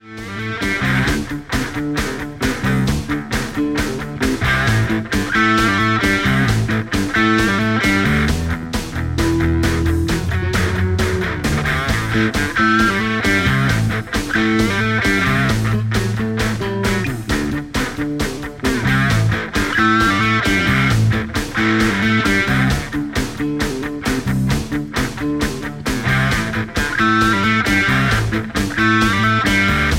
MPEG 1 Layer 3 (Stereo)
Backing track Karaoke
Pop, Rock, Oldies, 1960s